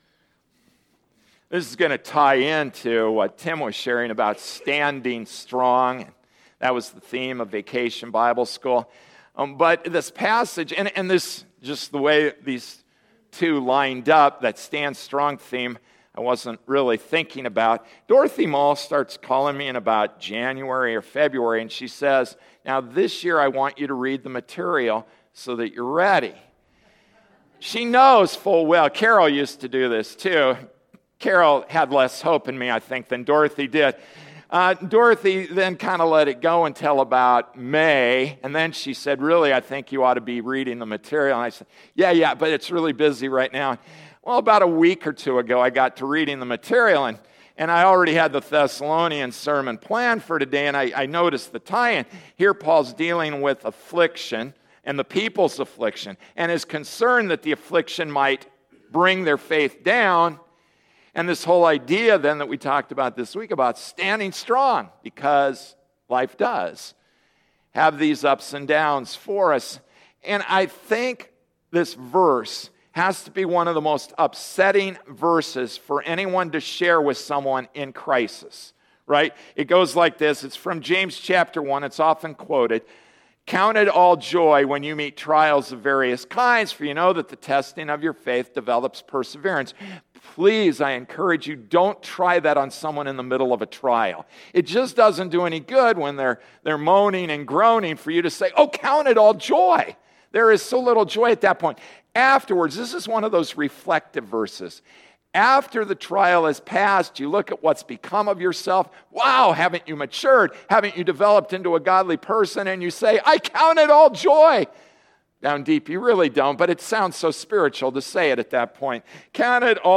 August 11, 2013 Stand Strong Passage: 1 Thessalonians 2:18-3:13 Service Type: Sunday Morning Service “Stand Strong” 1 Thessalonians 2:17-3:13 Introduction: James 1:2-4, yeah, yeah, yeah.